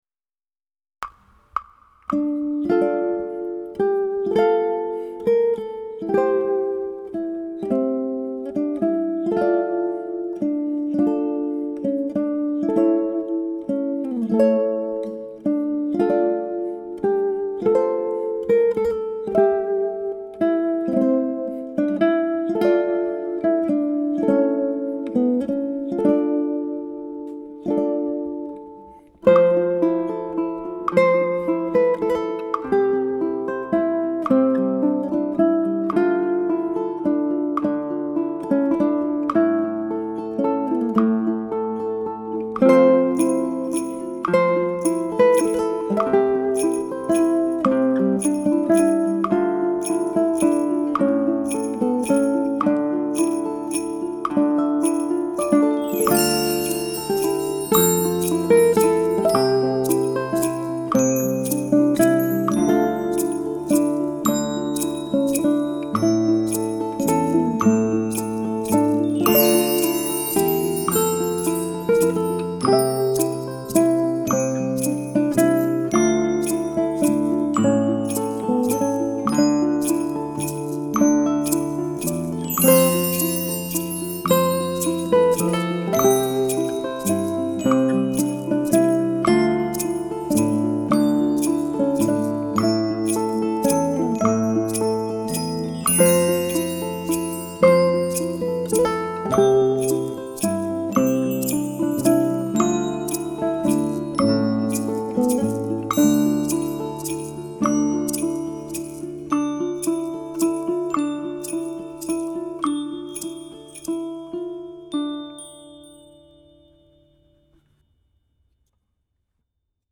The English folk song, Greensleeves, was initially published under the title "A Newe Northern Dittye of ye Ladye Greene Sleeves" in 1580.
ʻukulele